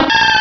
Cri de Macronium dans Pokémon Rubis et Saphir.